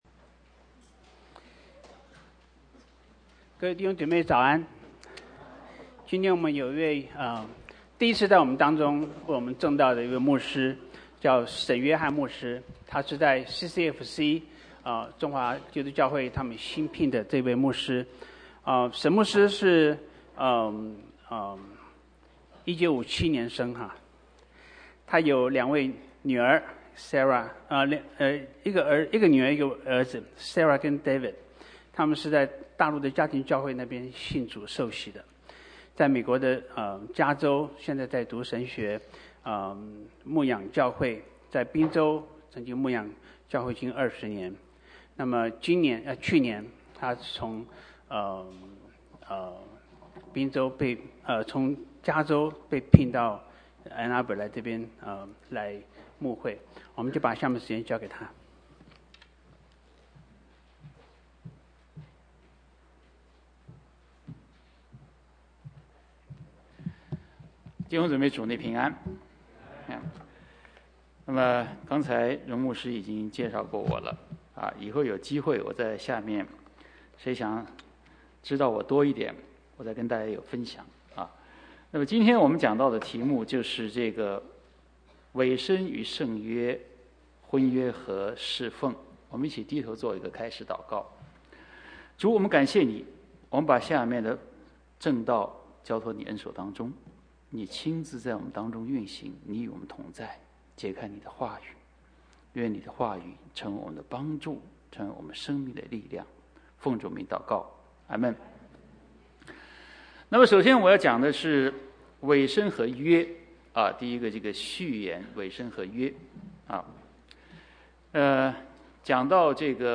Mandarin Sermons – Page 48 – 安城华人基督教会